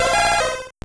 Cri d'Osselait dans Pokémon Or et Argent.